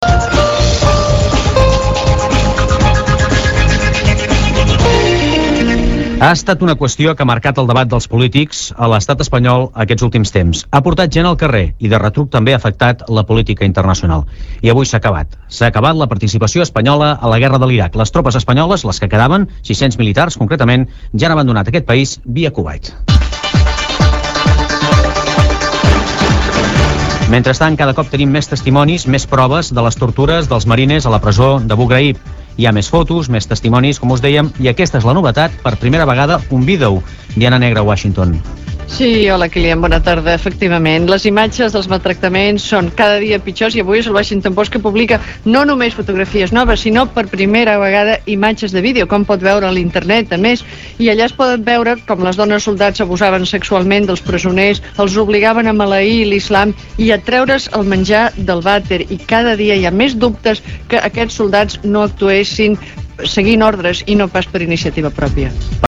Per començar, escoltarem tres fragments de diferents butlletins de notícies de ràdio. Corresponen al dia 21 de maig de 2004 i fan referència a la retirada de les tropes espanyoles de l’Iraq: